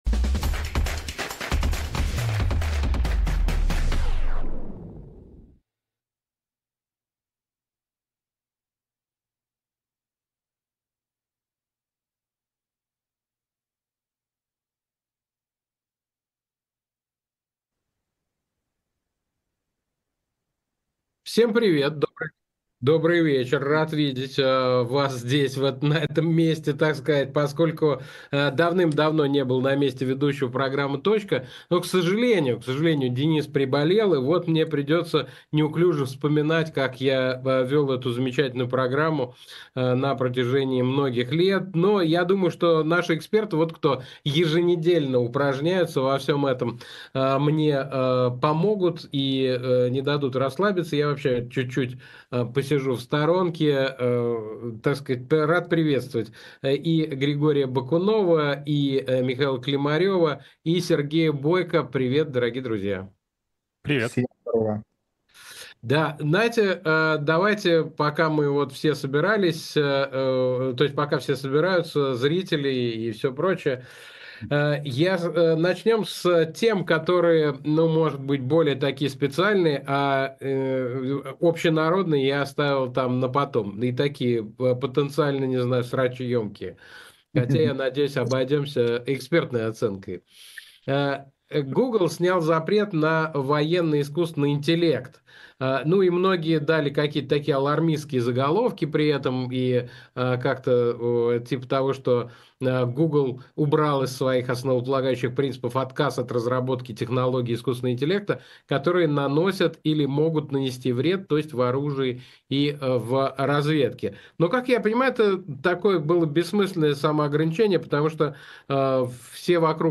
Эфир Александра Плющева с экспертами про интернет и технологии